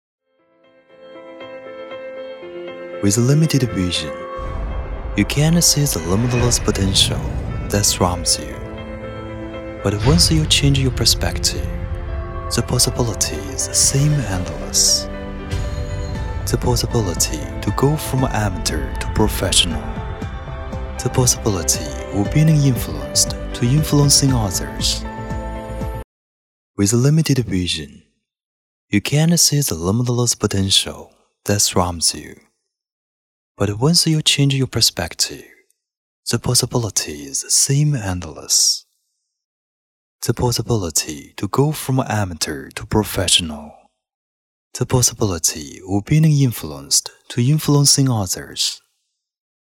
男47号配音师
It is energetic, vigorous, firm and passionate.
英文-广告-男47-TVC.mp3